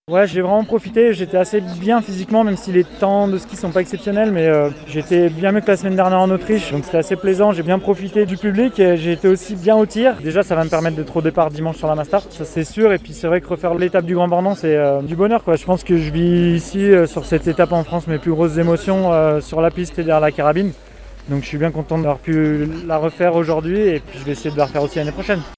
A la 27e place, avec deux fautes au tir, on retrouve une tête bien connue : Antonin Guigonnat, le local de l'étape, qui ne cache pas son plaisir d'être de retour.